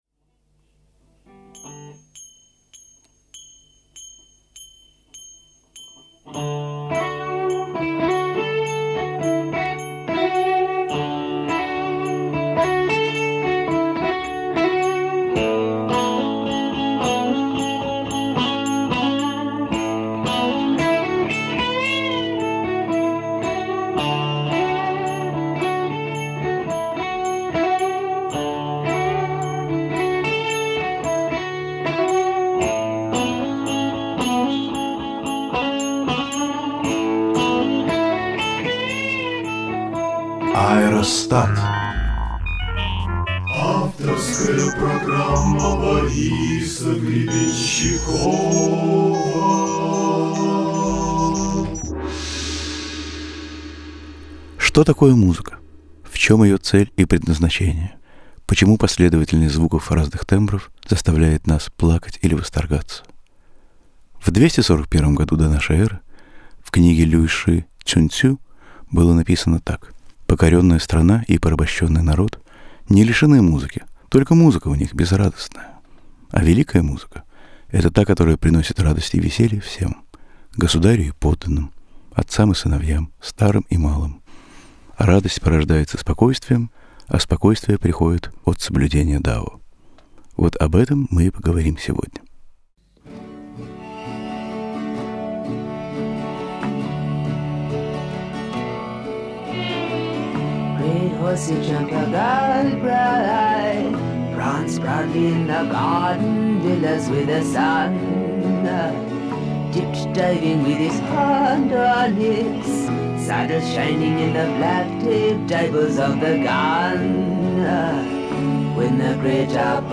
Аэростат mp3s (битые треки)